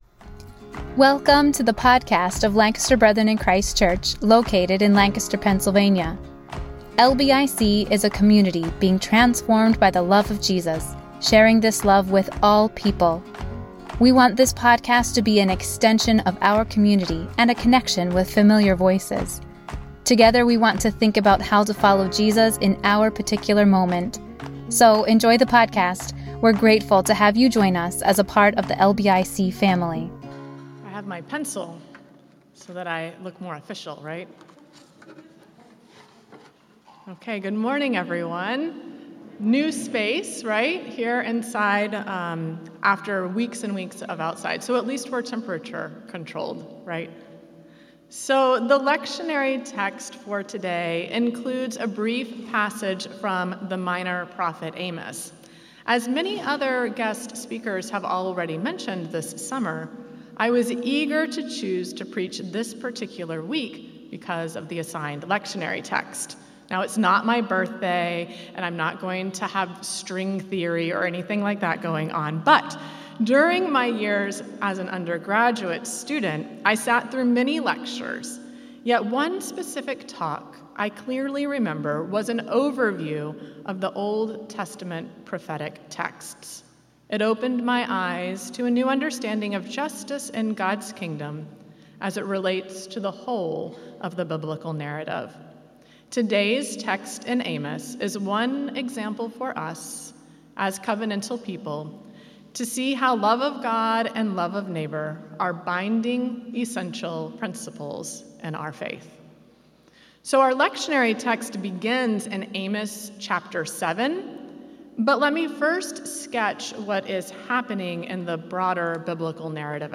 A message from the series "Ordinary Time."